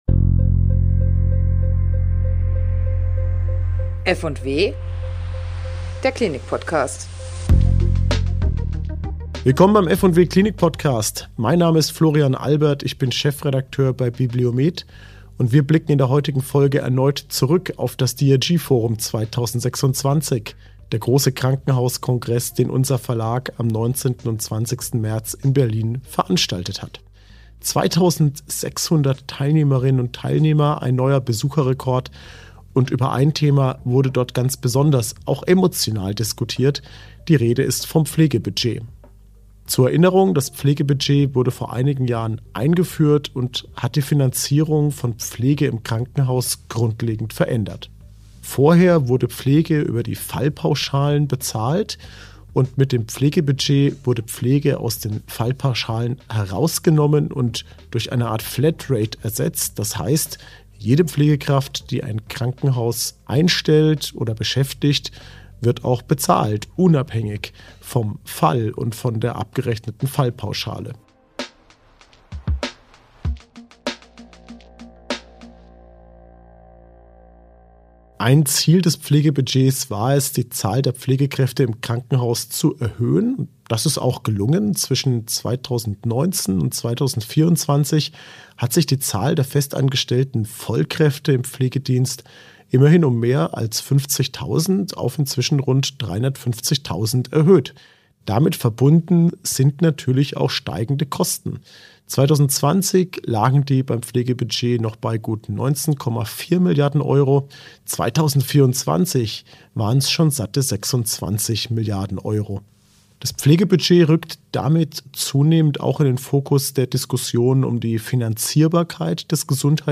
Auf dem DRG‑Forum 2026 diskutierte am 20. März ein prominent besetztes Panel über Zweck, Wirkung und Zukunft dieses Finanzierungsinstruments – von Fehlanreizen über pflegefremde Tätigkeiten bis hin...